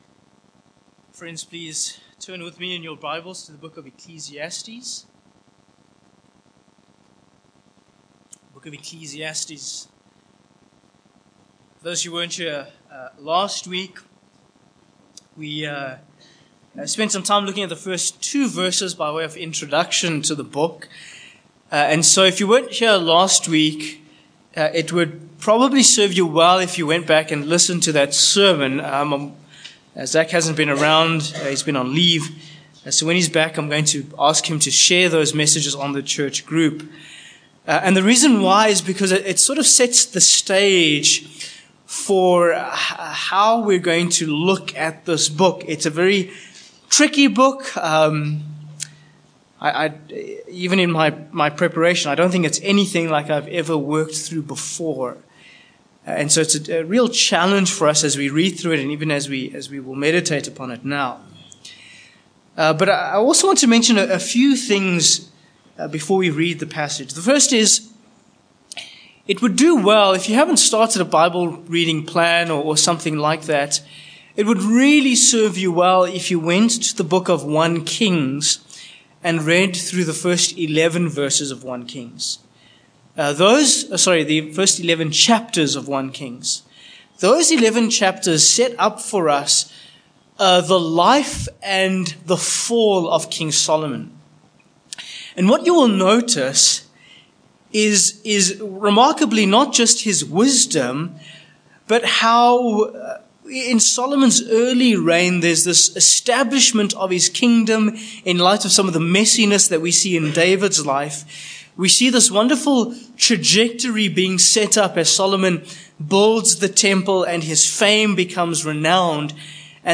Ecclesiastes 1:1-11 Service Type: Morning Passage Ecclesiastes 1:1-11 1.